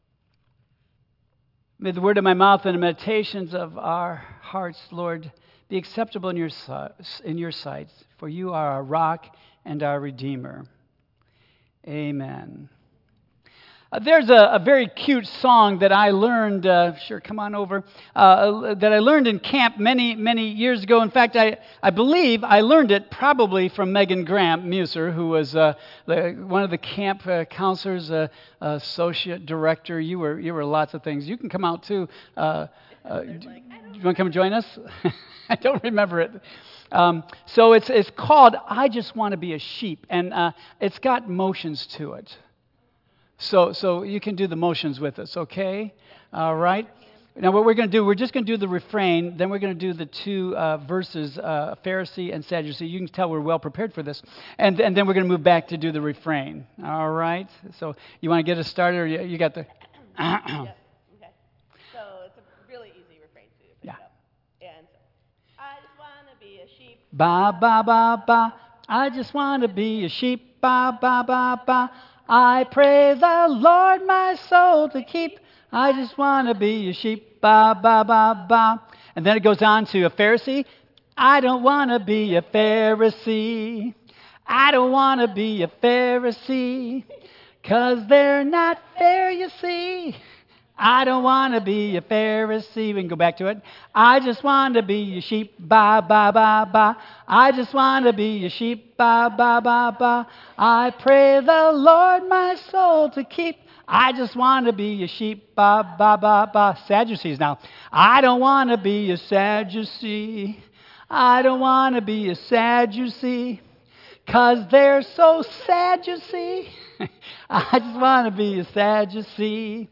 Tagged with Lent , Michigan , Sermon , Waterford Central United Methodist Church , Worship Audio (MP3) 8 MB Previous The Superficial Scribes Next The Clash of an Empire and Kingdom